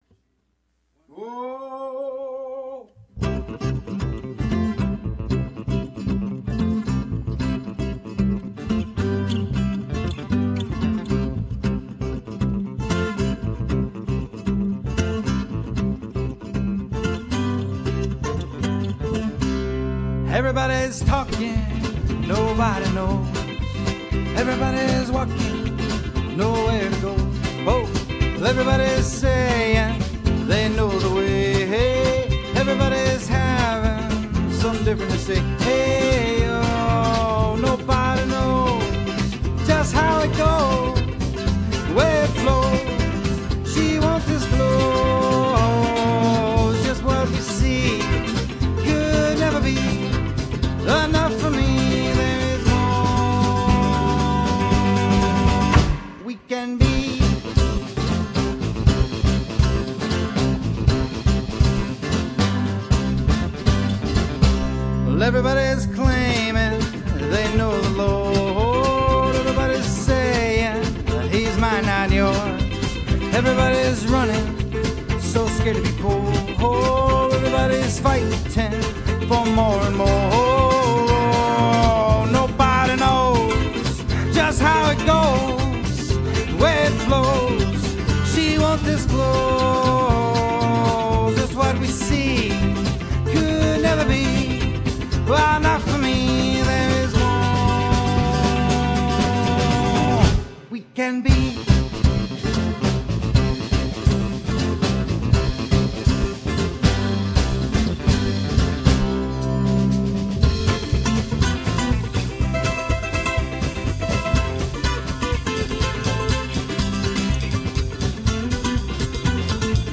Slamfunkingroovingreengrass Moozik that Swings and Snorts.